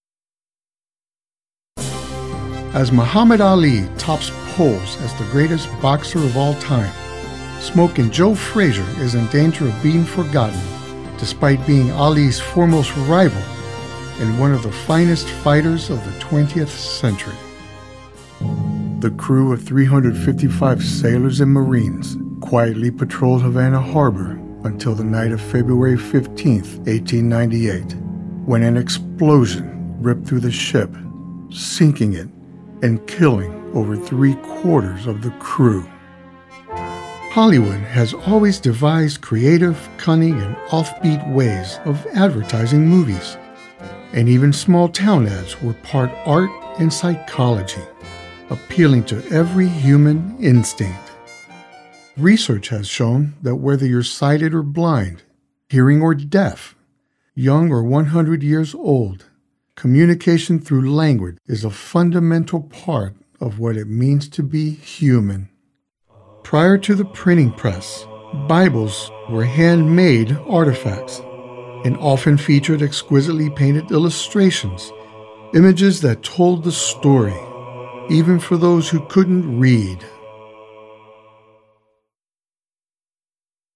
Narration Demos
English - USA and Canada
Middle Aged
Senior
I can be soft spoken but also sincere, assertive, conversational, friendly, casual, informative.
I have an in-house home recording studio with a Rode NT1-A-MP Stereo Studio Vocal Cardioid Condenser Microphone, Focusrite Scarlett 2i2 3rd Gen USB Audio Interface and I use Audacity for my recording program.